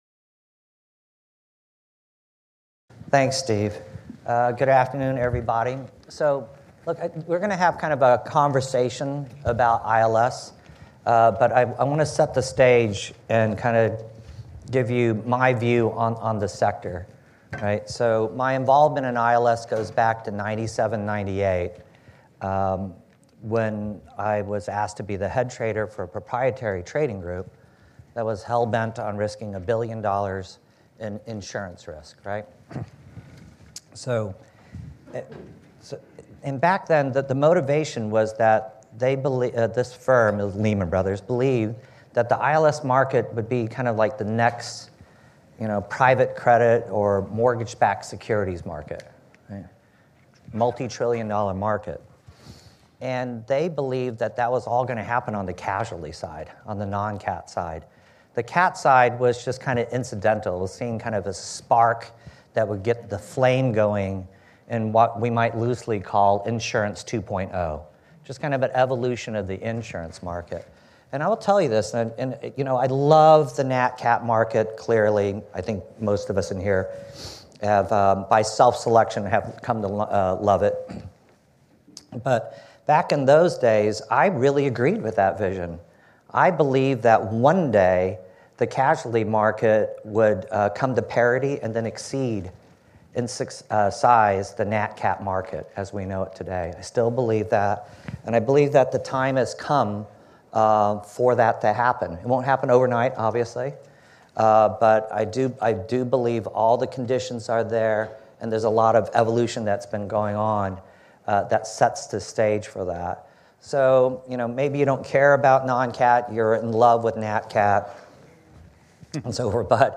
This episode features a casualty insurance-linked securities (ILS) focused panel discussion from our ILS NYC 2025 conference. Listen for unique insights into the developing casualty ILS market, what investors need to know about this asset class, and how cedents can benefit from access to efficient capacity from the capital markets.